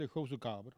Langue Maraîchin
locutions vernaculaires
Catégorie Locution